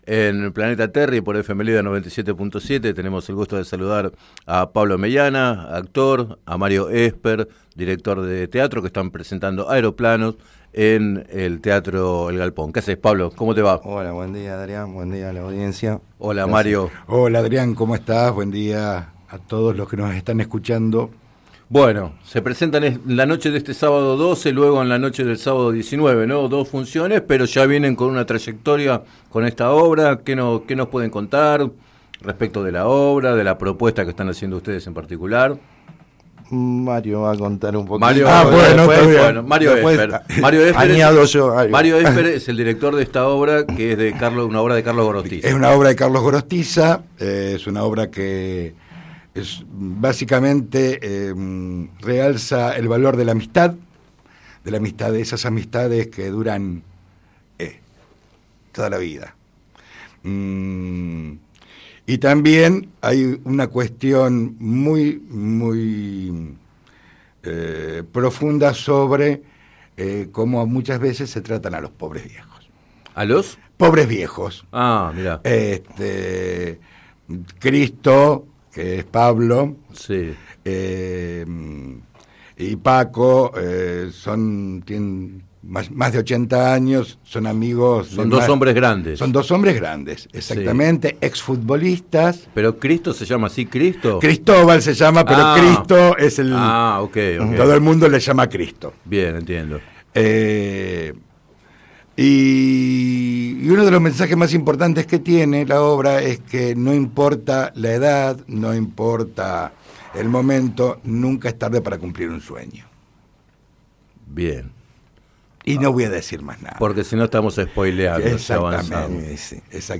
Entrevistados en el programa Planeta Terri de FM Líder 97.7